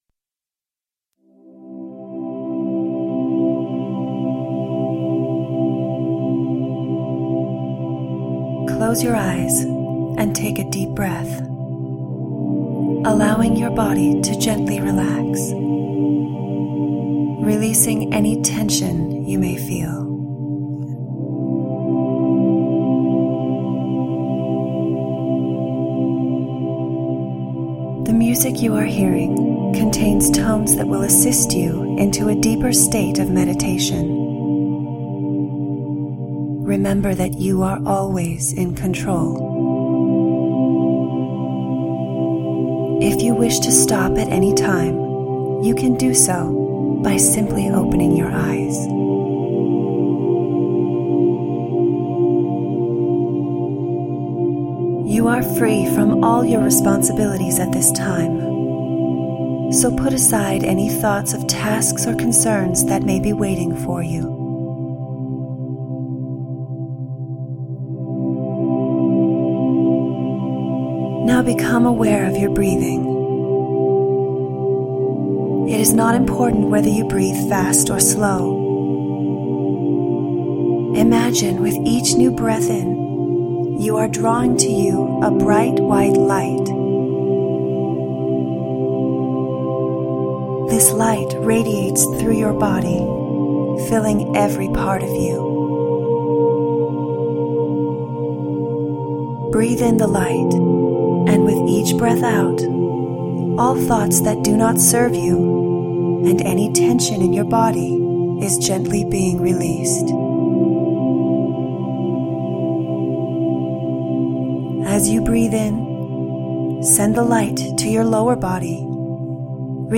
Part-II-15-Min-Wealth-Meditation.mp3